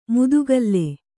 ♪ mudugalle